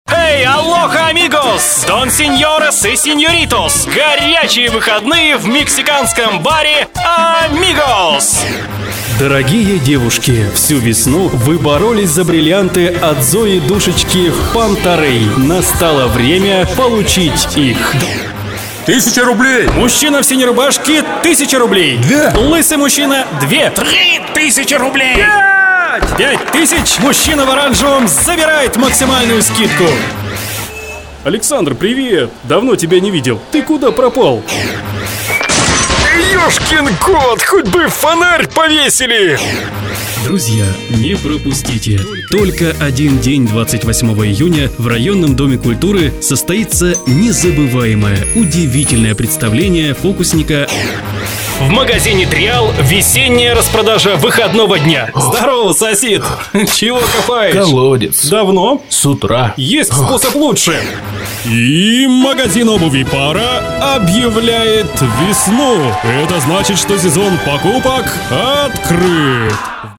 Тракт: Микрофон: Rode NTK Звуковая карта: Focusrite 2i2 Акустическая комната.